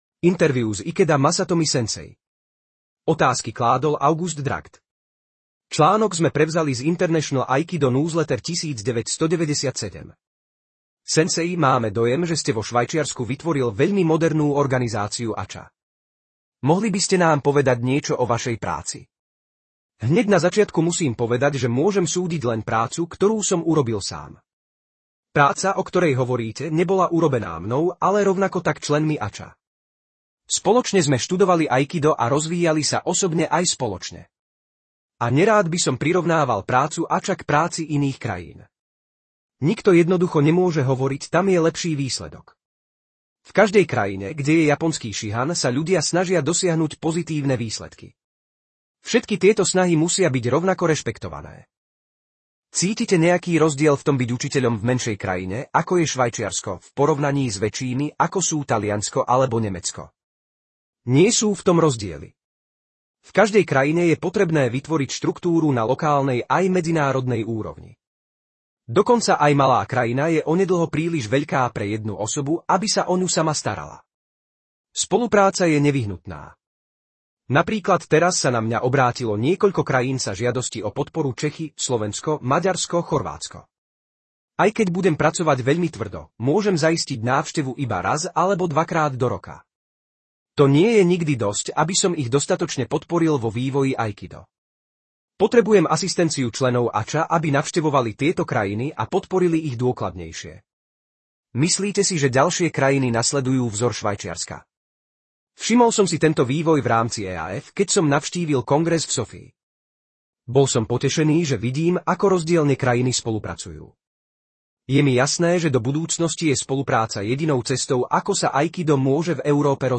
Rozhovor: International Aikido Newsletter 1997